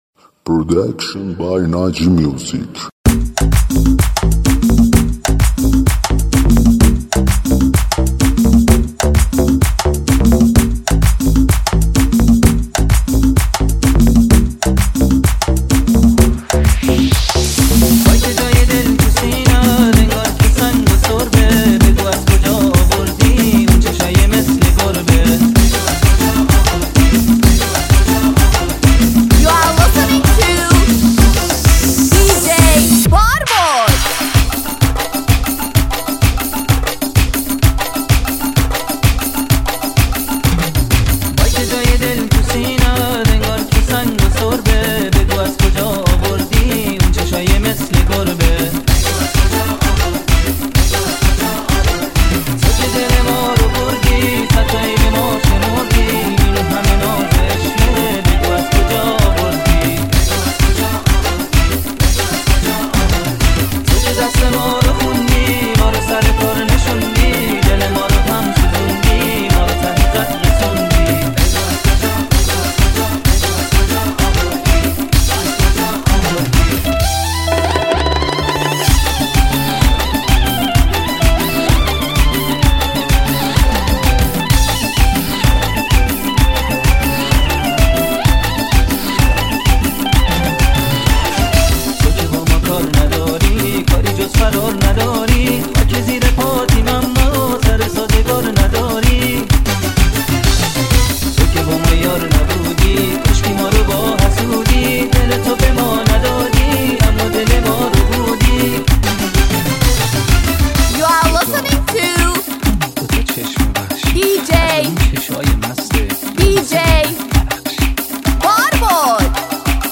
ریمیکس شاد تریبال رقصی